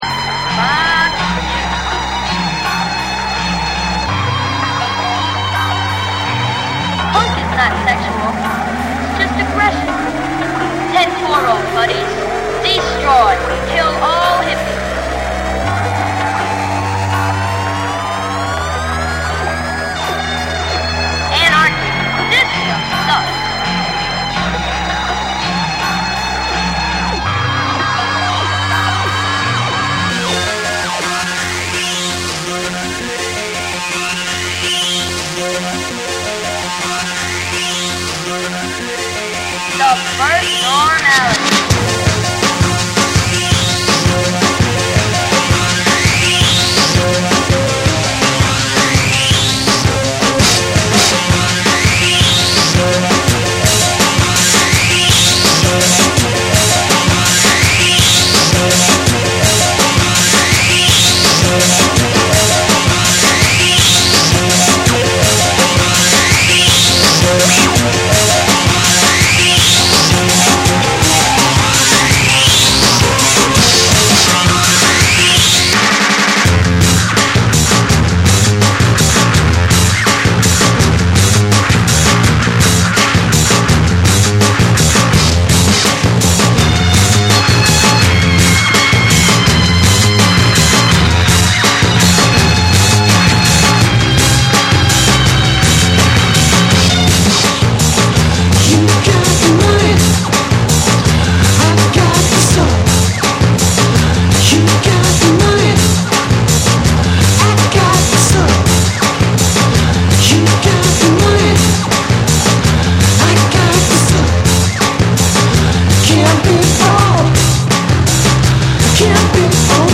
ロックとエレクトロニクス、ダブの感覚が交錯する、当時のバンドの先鋭的なサウンドを象徴する一枚。
NEW WAVE & ROCK